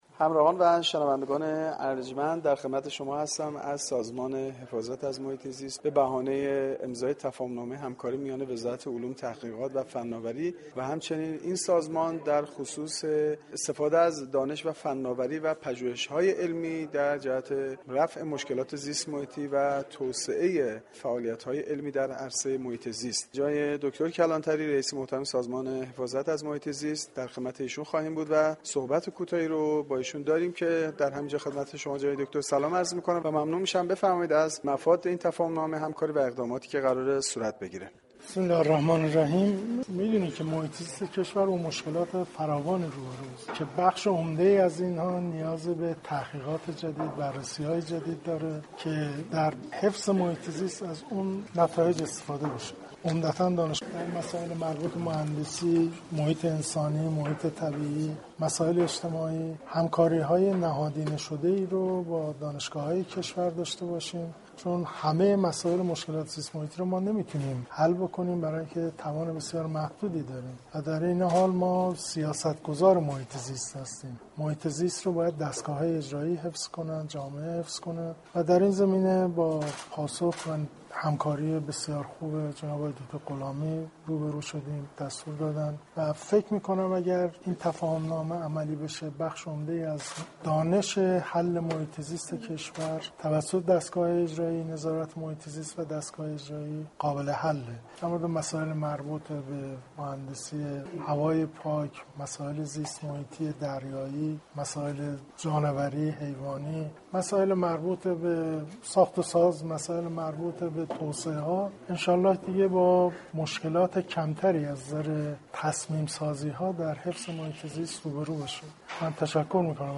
دكتر غلامی وزیر علوم ، تحقیقات و فناوری در گفتگوی اختصاصی با گزارشگر رادیو فرهنگ درباره این همكاری ها گفت